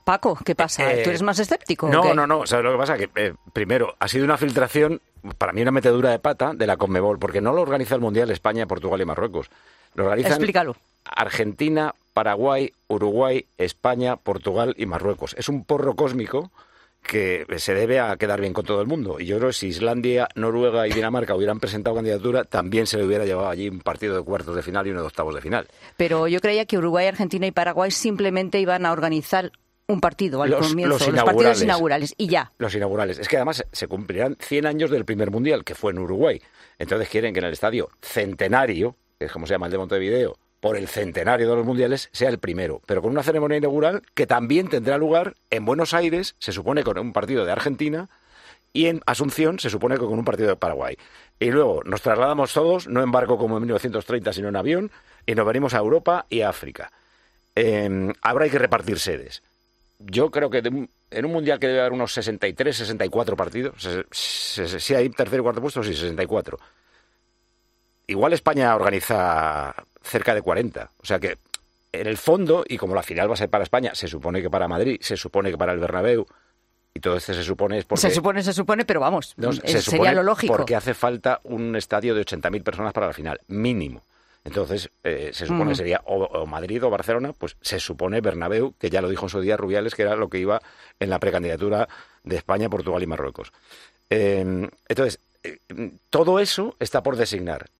"Ha sido una metedura de pata de la CONMEBOL", acusaba un Paco González que analizaba la noticia en La Tarde de COPE, todavía con la sensación de haber asistido a un anuncio oficial de forma un tanto caótica.